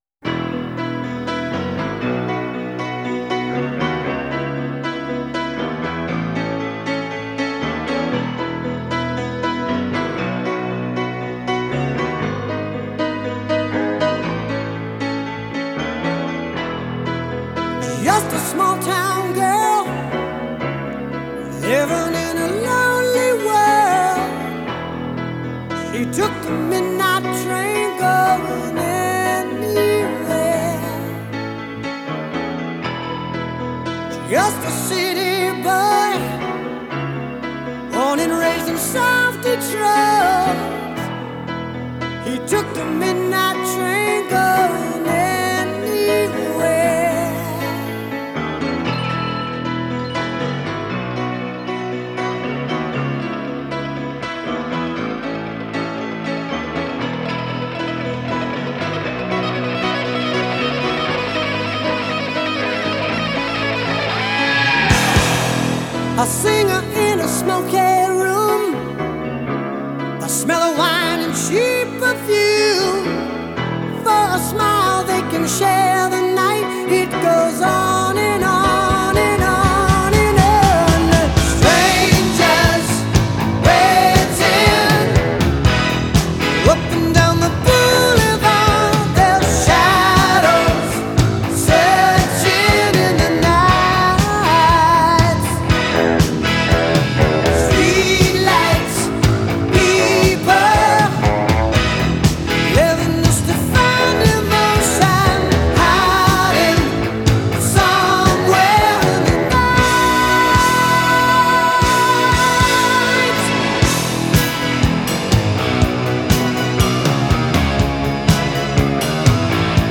O clássico do rock